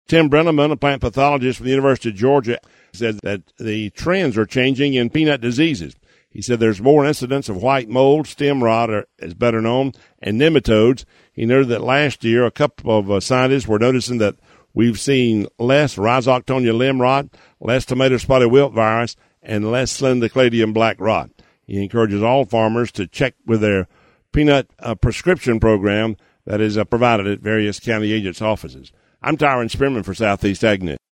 a report on how peanut disease trends are changing and what growers are being urged to do.